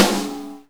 drum-hitclap.wav